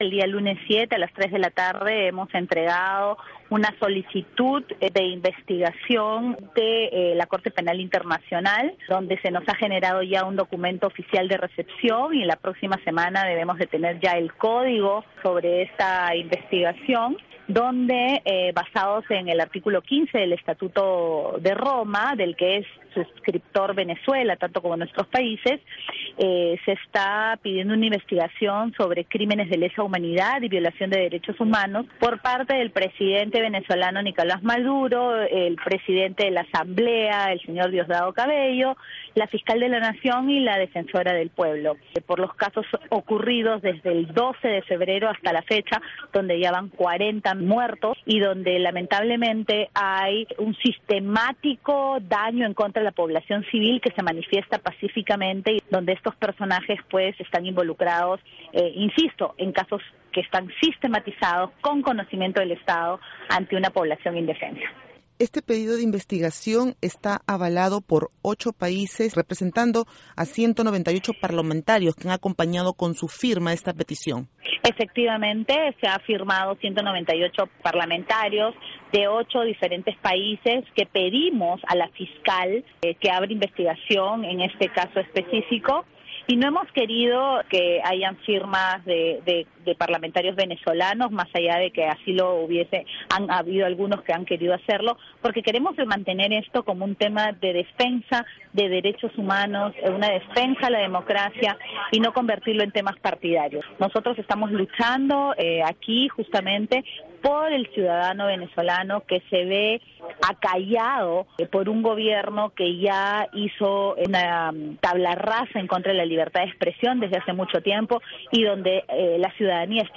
Escuche la entrevista con Cecilia Chacón
La Voz de América conversa con la parlamentaria de Fuerza Popular de Perú, Cecilia Chacón, quien confía que pedido de investigación contra Maduro será aceptado por Corte Penal Internacional de La Haya.